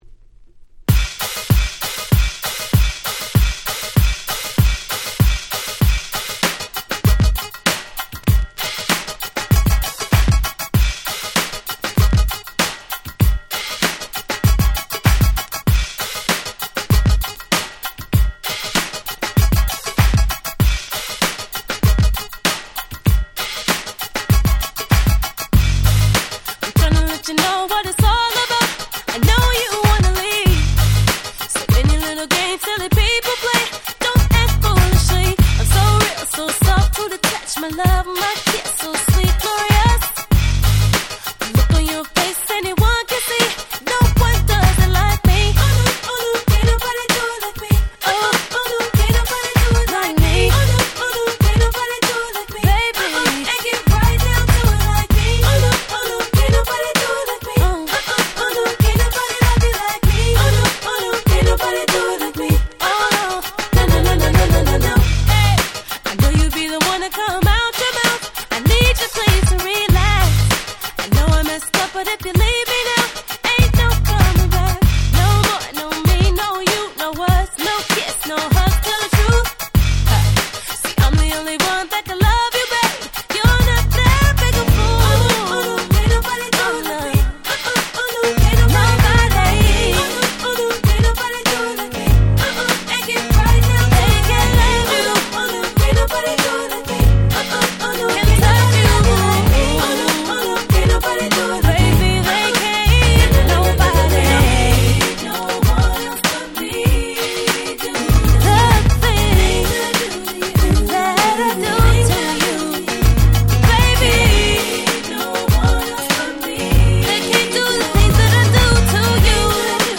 03' Nice R&B !!
当時のメインストリームっぽいBeatではある物の哀愁系なメロディーが心くすぐるめちゃ良い曲！！